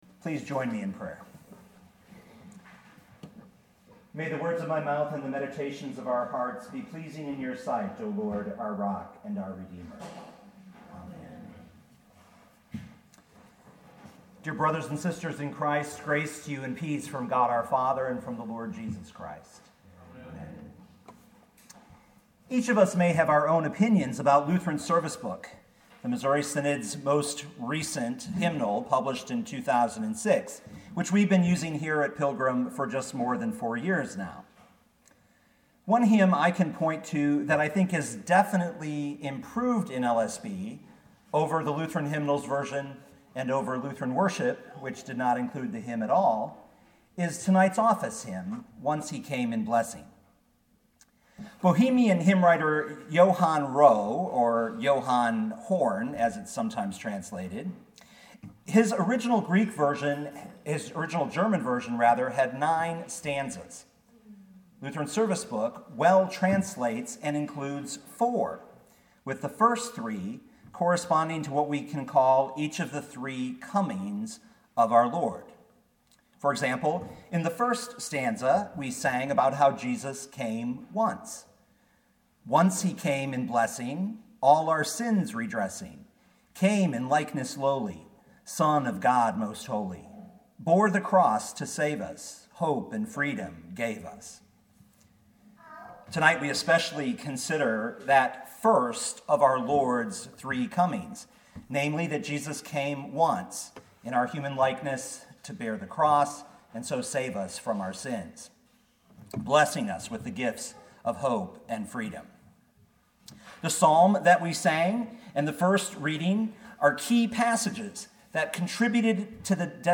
Sermons
Midweek Advent I, December 06, 2017